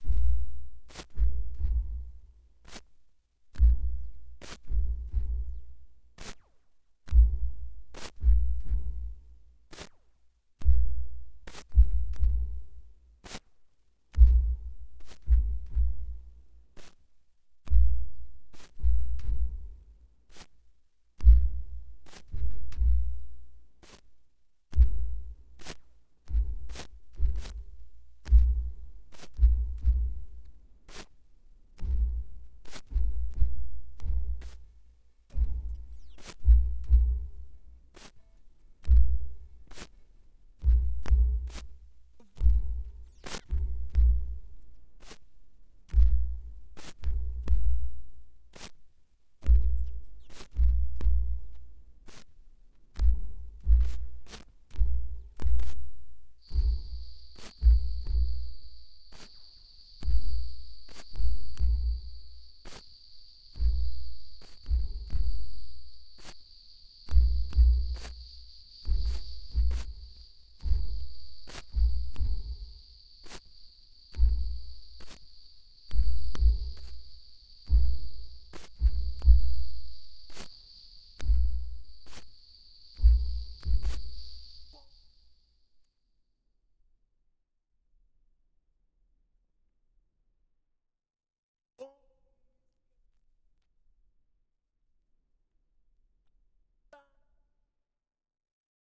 В итоге разностный файл имеет такие параметры: Увеличил его громкость на 72 dB (6-ю вольюм плагинами по 12 dB), и услышал то что во вложении. Как вы понимаете, это фактически лишь бочка-рабочка, и немножко ещё каких-то клавиш, с 1:25 идёт яма с клавишами и вокалом - там практически совсем ничего. Ни оверов, ни гитар, ни баса, ни подавляющего большинства клавиш, ни вокала ни бэков - ничего этого не слышно В ПРИНЦИПЕ.